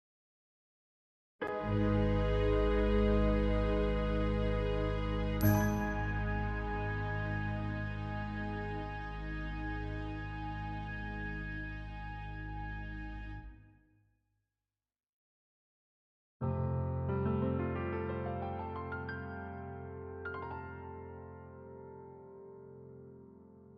- Orchestral and Large Ensemble - Young Composers Music Forum
The Long Arctic Midnight - Feat. Spitfire VST sounds, and fixes to issues in notation, beaming, and voicings!